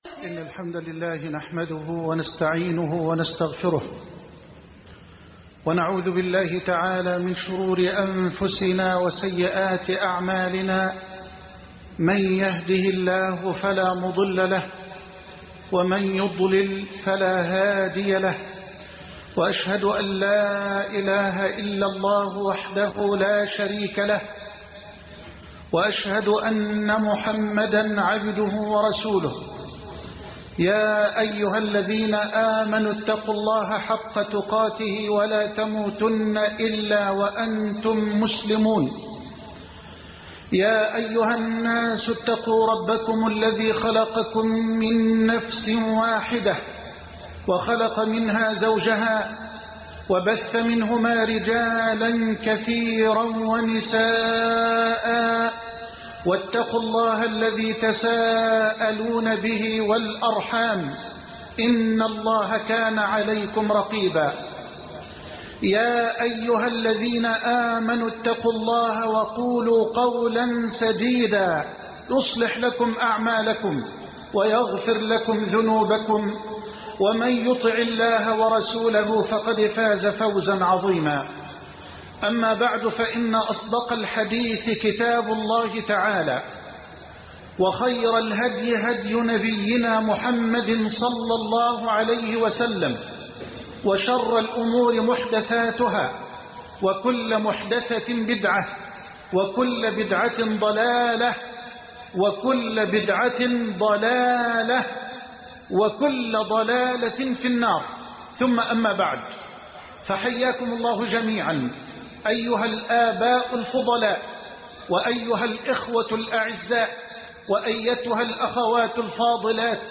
شبكة المعرفة الإسلامية | الدروس | عاشوراء بين الاتباع والابتداع - محمد حسان |محمد حسان